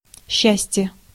Ääntäminen
IPA: [ˈɕːæ.sʲtʲjə]